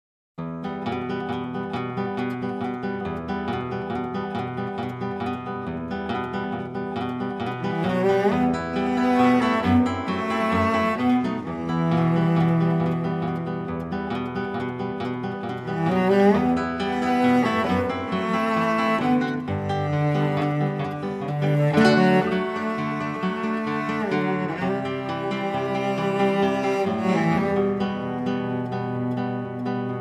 violoncelle
guitare